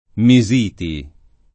[ mi @& ti ]